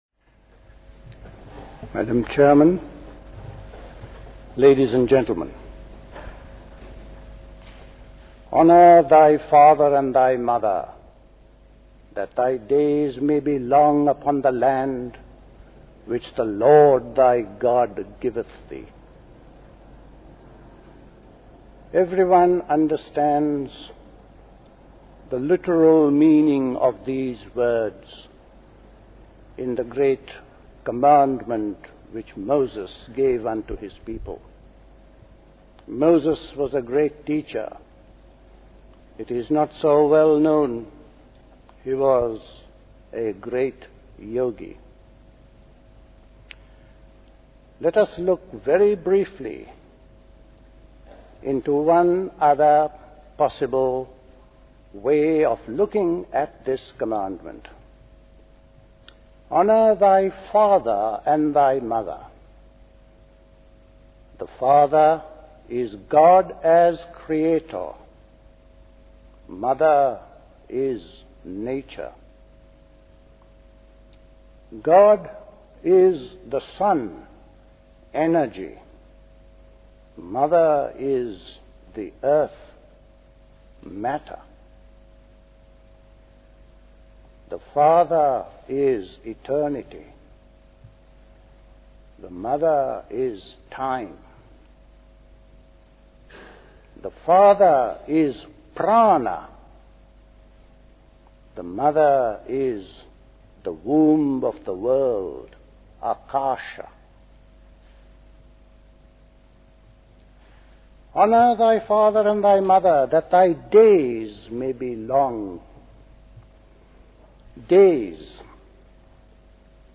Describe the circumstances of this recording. A short passage near the end of this talk sounds quite different from the rest, as though it has been added later.